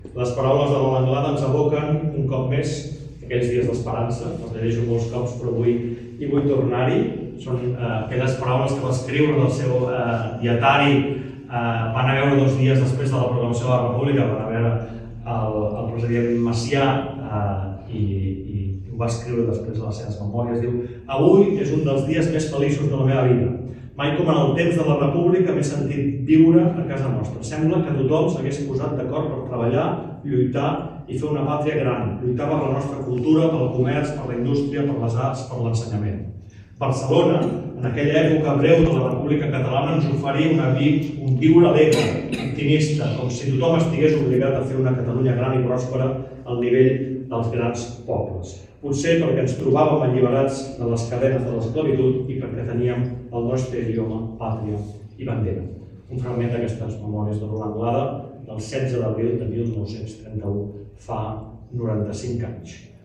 La Sala de Plens ha acollit aquest dimarts 14 d’abril lacte d’homenatge als tres alcaldes republicans que van haver de marxar a l’exili, Eduard Simó, Marcel·lí Garriga i Josep Rosselló.
Durant el seu discurs, l’alcalde també ha llegit un fragment de les memòries de la Lola Anglada, dos dies després de la proclamació de la Segona República: